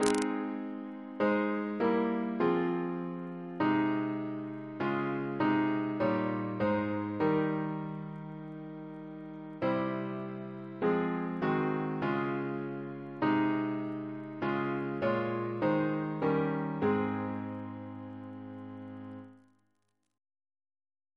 Double chant in A♭ Composer: John Stafford Smith (1750-1836) Reference psalters: ACB: 357; PP/SNCB: 137